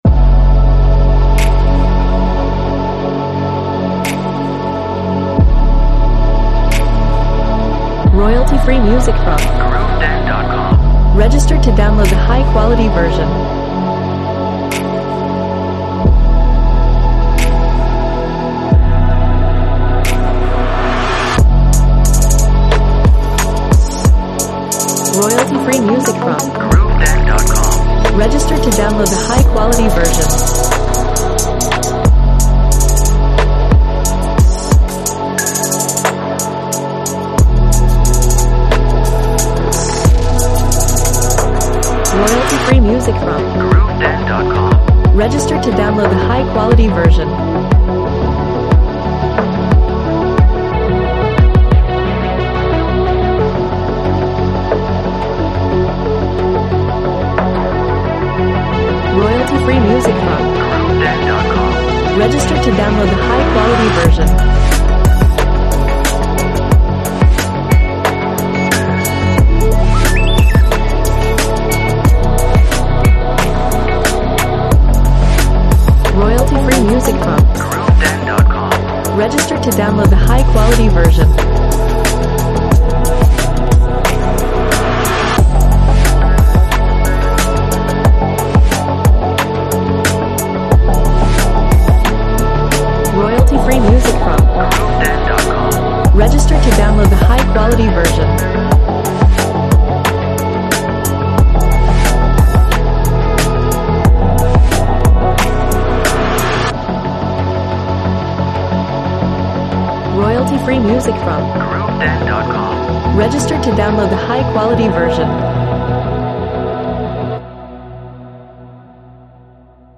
Instruments: Pads, drums, synth, piano, bass, 808, effects.